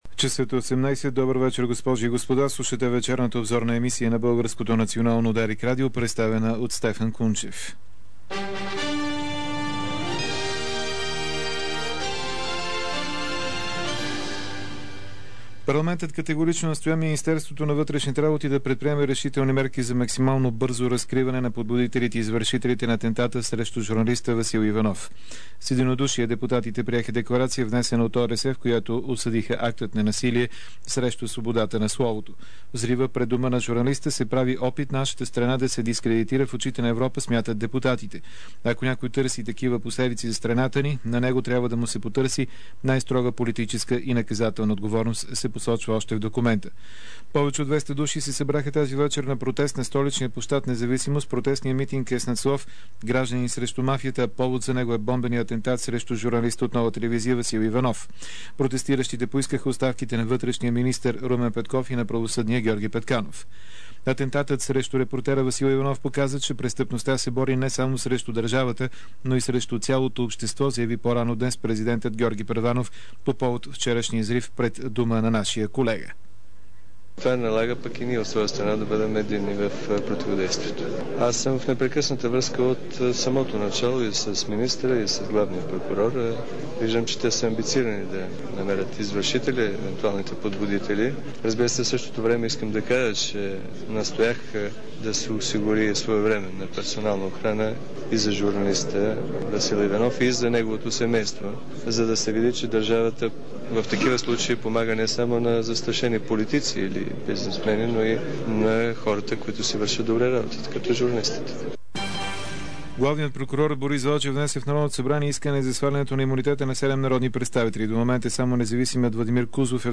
DarikNews audio: Обзорна информационна емисия 07.04.2006